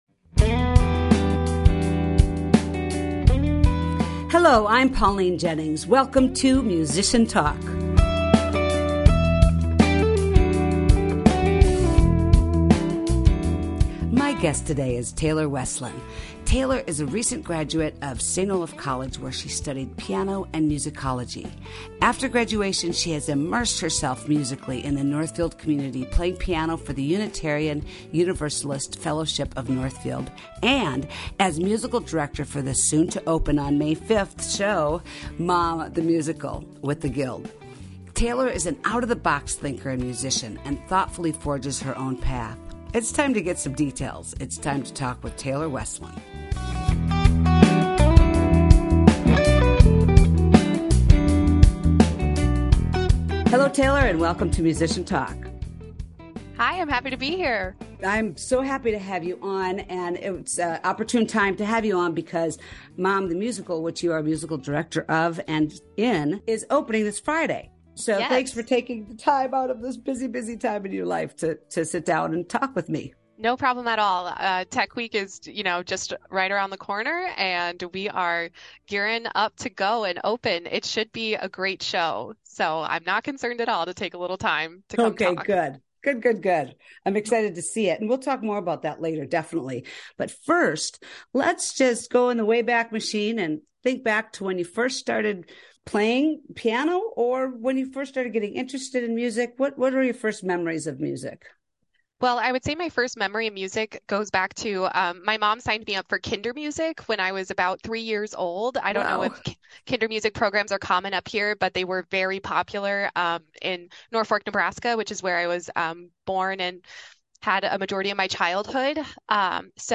Find out more about her musical journey, listen and discuss a couple of her piano performances, delve into the music quote of the week, dish about her best gig/worst gig, and so much more!